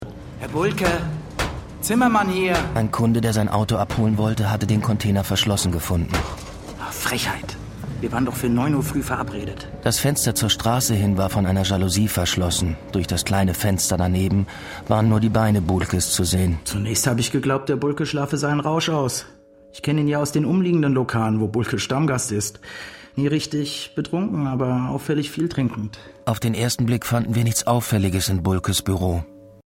Meine Stimme klingt unverfälscht, jung und sympathisch und ist vielseitig einsetzbar für jugendliche bis männlich-zärtliche Charaktere.
Radio Tatort. „Plastik im Apfelgarten“
Rolle: Zimmermann (NR)